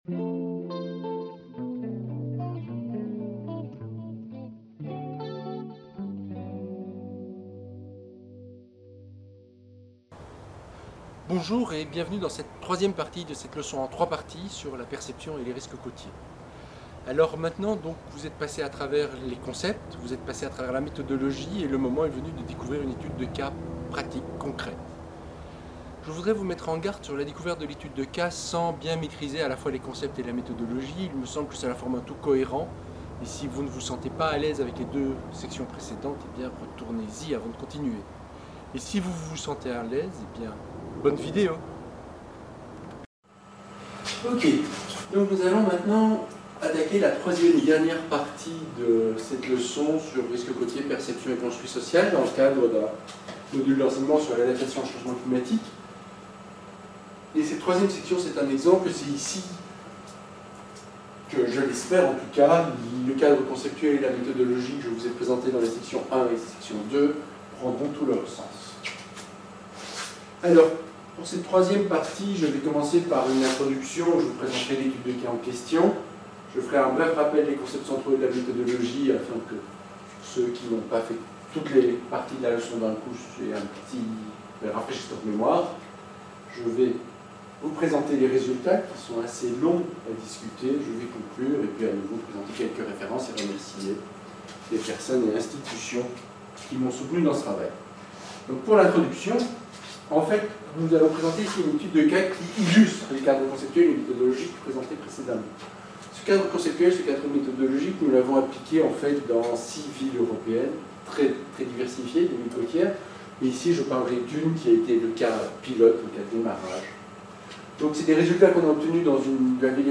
Il s'agit de la troisième partie d'une leçon en trois parties sur l'analyse des perceptions dans un contexte d'adaptation côtière aux changements climatiques.